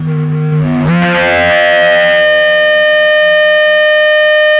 RADIOFX  3-R.wav